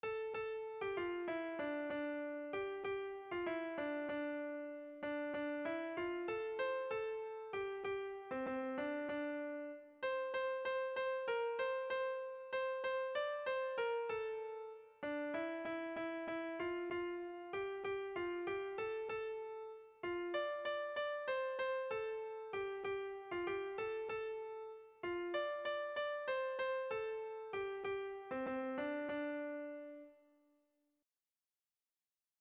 Dantzakoa
ABDE